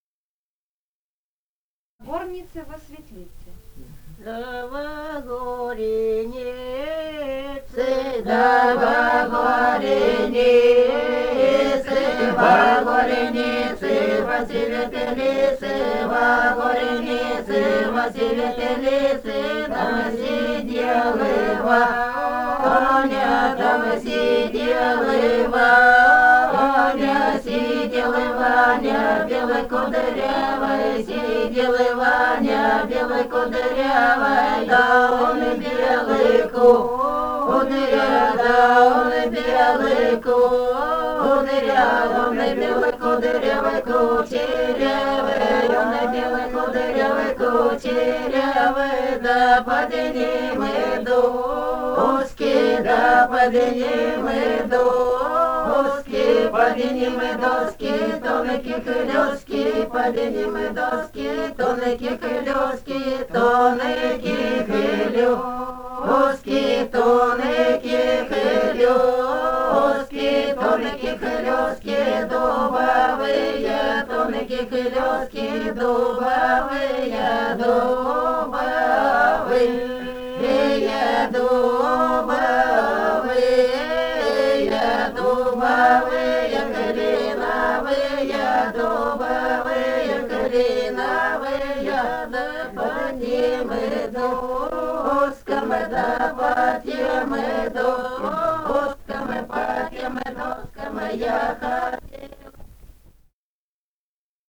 Этномузыкологические исследования и полевые материалы
Алтайский край, с. Михайловка Усть-Калманского района, 1967 г. И1001-14